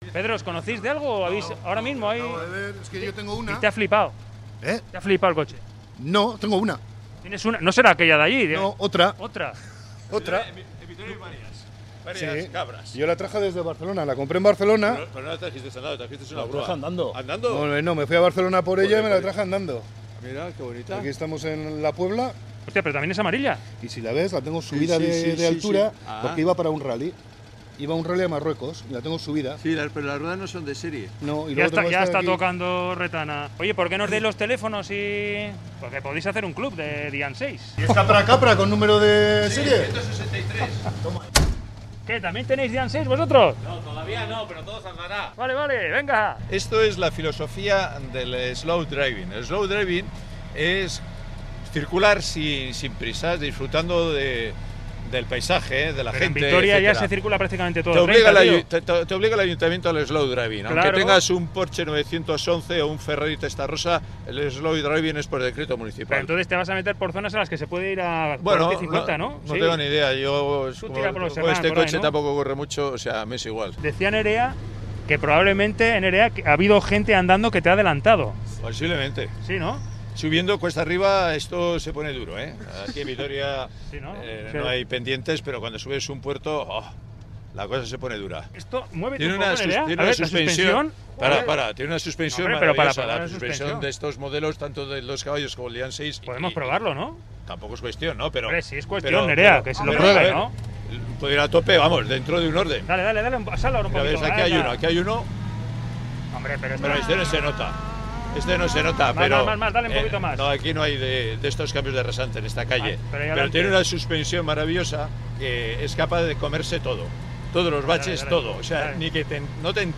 Audio: Reportaje: 'Slow driving'. En Dyane 6 por Vitoria (parte 2)